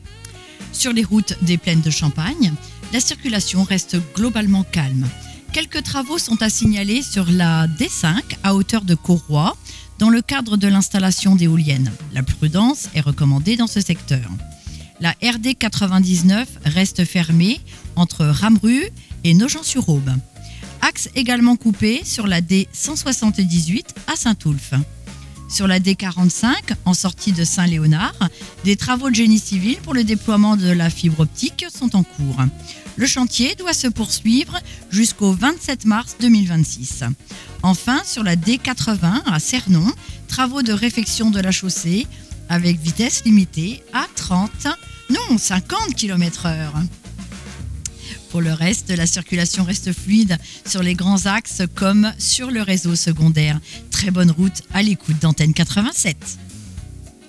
Bienvenue dans l’InfoRoute des Plaines – votre bulletin circulation du matin !Chaque jour, nous vous accompagnons sur les routes des Plaines de Champagne avec un point complet sur les conditions de circulation, afin de vous aider à voyager en toute sérénité.